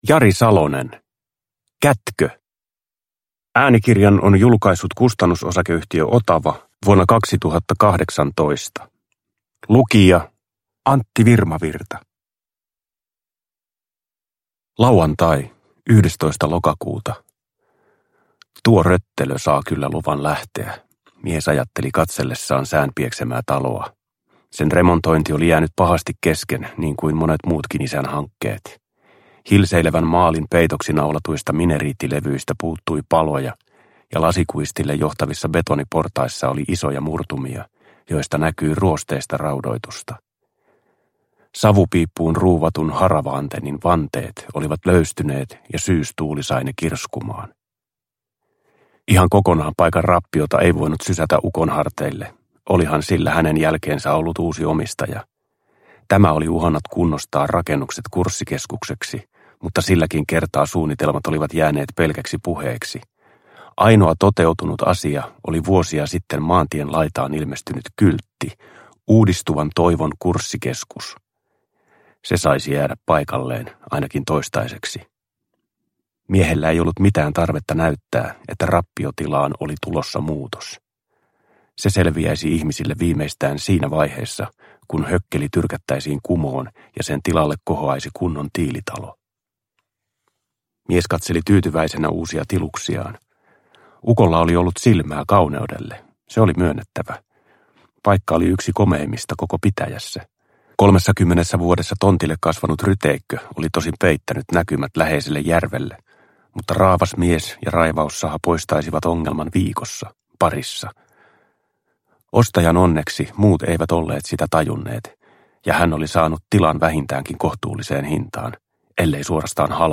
Kätkö – Ljudbok – Laddas ner
Uppläsare: Antti Virmavirta